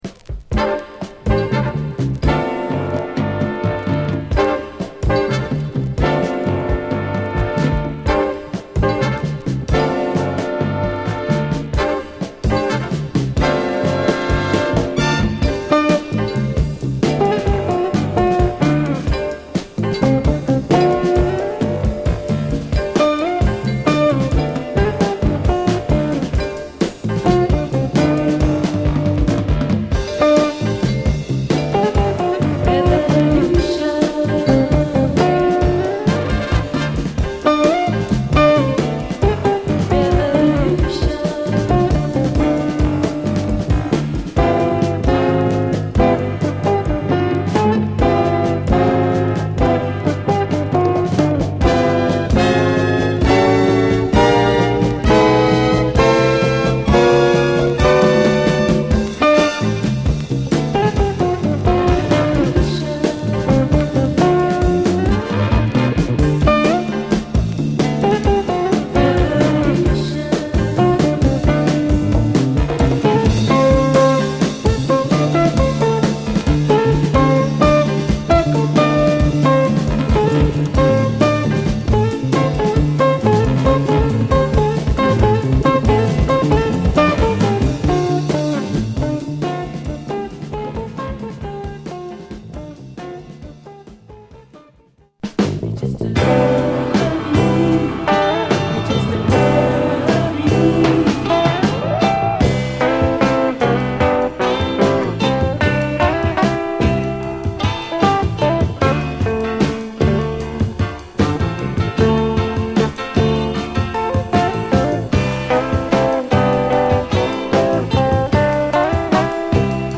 アーシーなジャズファンク好盤です！